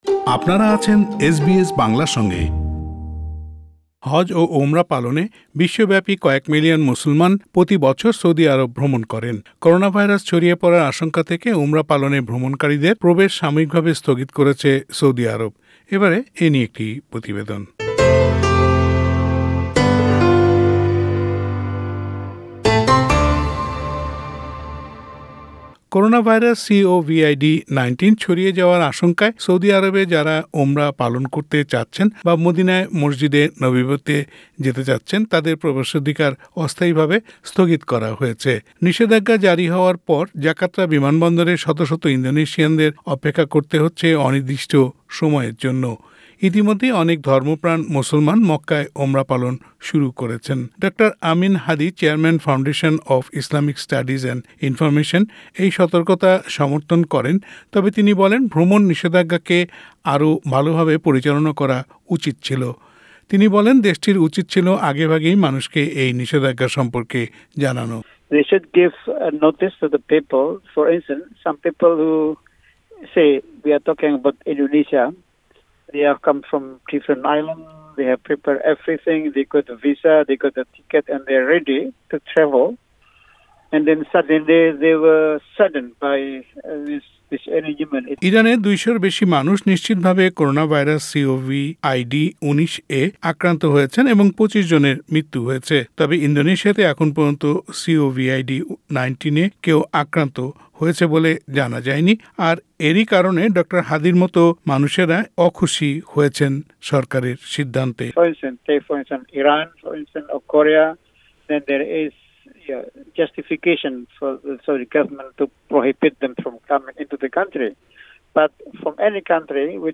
প্রতিবেদনটি শুনতে উপরের লিংকটিতে ক্লিক করুন